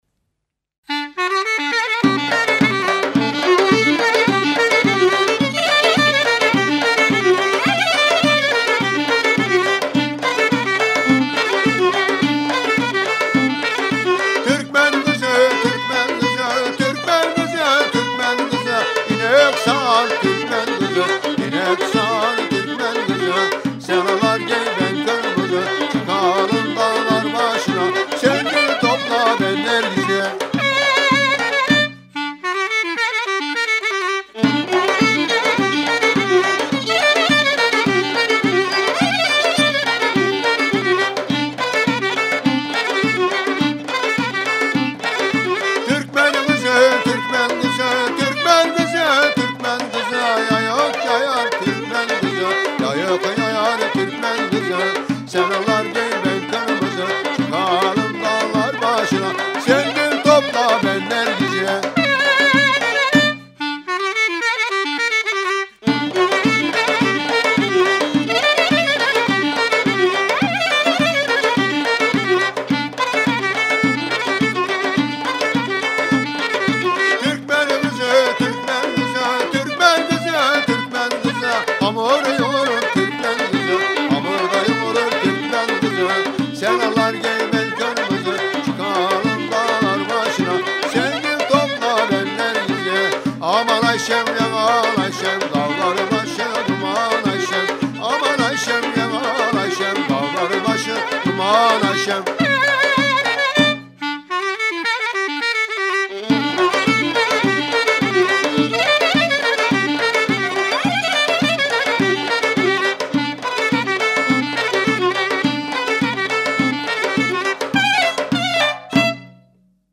Sözlü, Sözsüz Yöresel Müzikler